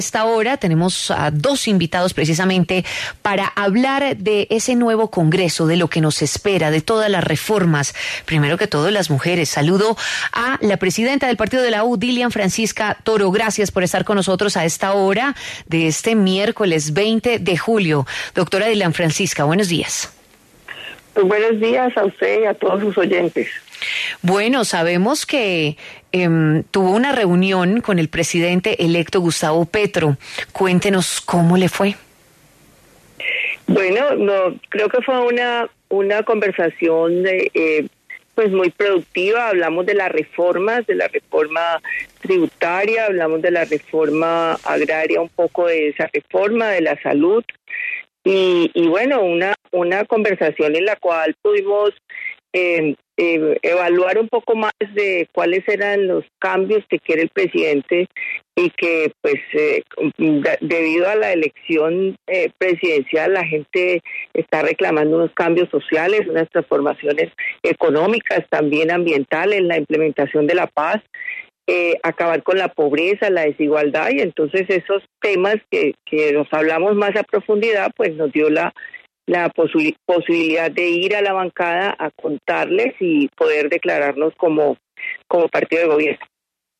Según comentó Toro en entrevista con La W, tuvo una conversación muy productiva con Petro, en donde hablaron sobre diferentes aspectos, especialmente los sociales y económicos.